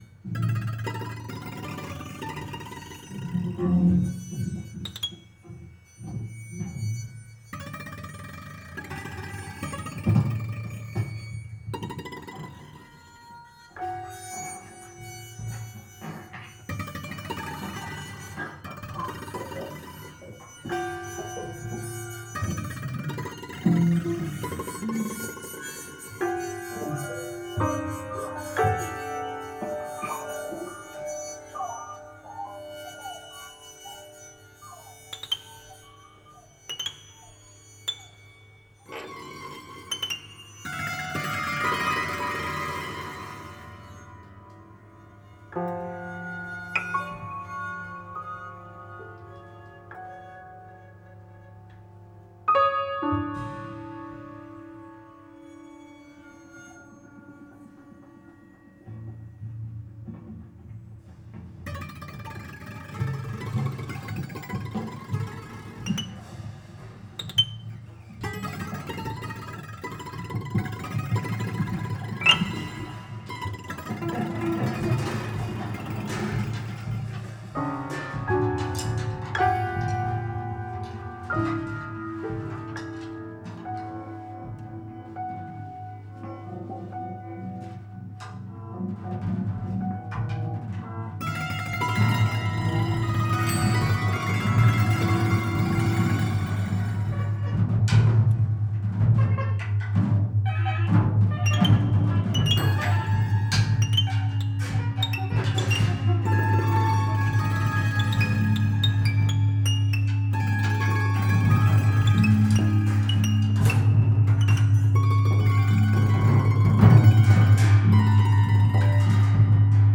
AD HOC QUARTET:
piano
drums